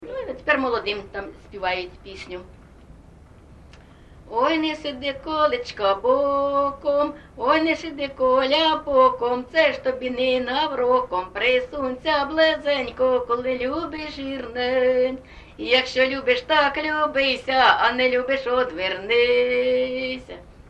ЖанрВесільні
Місце записум. Маріуполь, Донецька обл., Україна, Північне Причорноморʼя